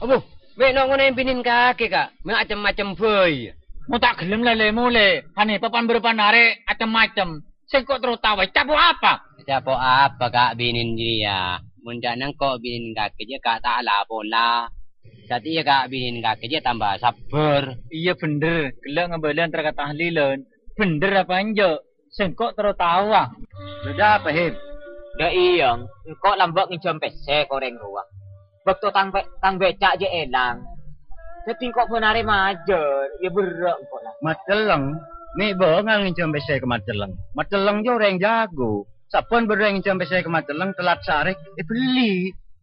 16 September 2012 at 9:14 pm Actually, the vowel I spelled as [ø] is actually not a rounded tense mid-front vowel (as per that phonetic symbol) but the high back tense unrounded vowel [ɯ].